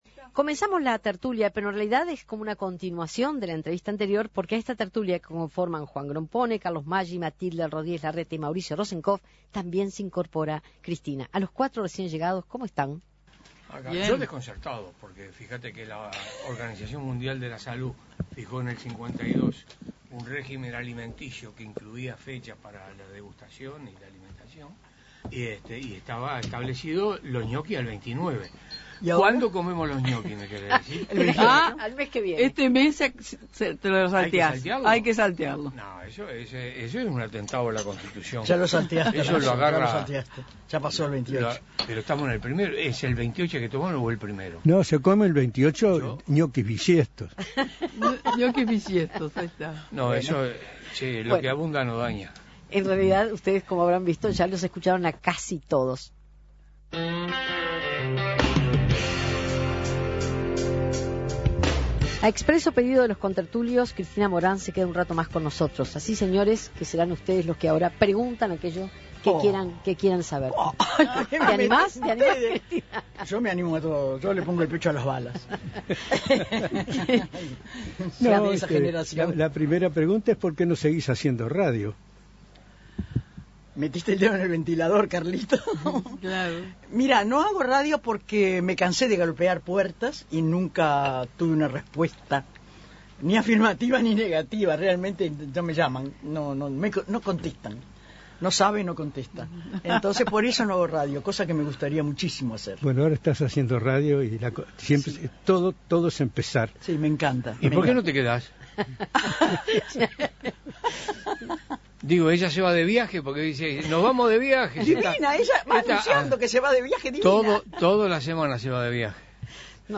Cristina Morán se suma a La Tertulia y responde las preguntas de Juan Grompone, Carlos Maggi, Matilde Rodríguez Larreta y Mauricio Rosencof